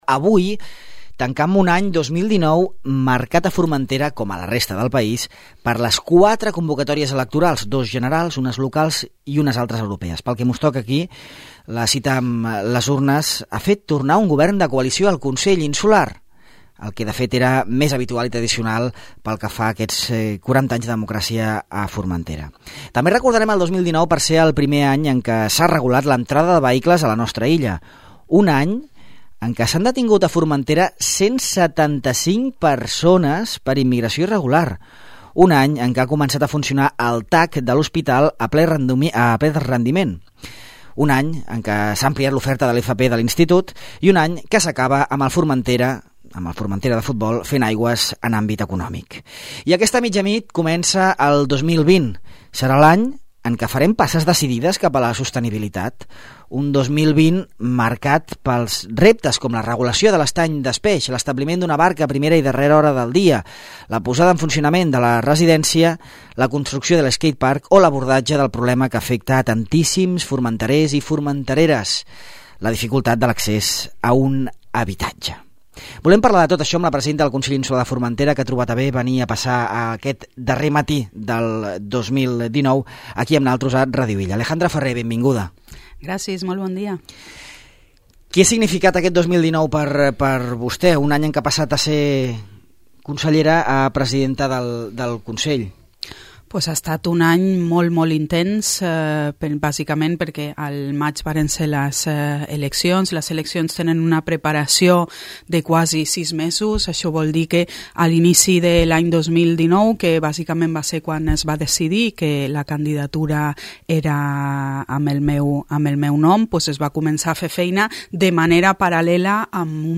Amb la presidenta del Consell, Alejandra Ferrer, fem un repàs de com ha anat aquest 2019 i de quins són els reptes que ha d’afrontar la nostra illa de cara a l’any vinent.